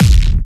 • Analog Distorted Mid Tail Techno One Shot Kick.wav
Analog_Distorted_Mid_Tail_Techno_One_Shot_Kick_iyT.wav